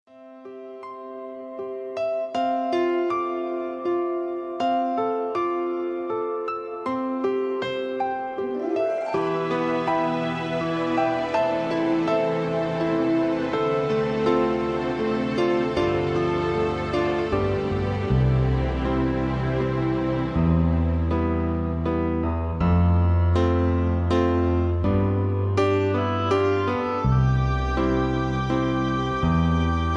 backing tracks
pop music, r and b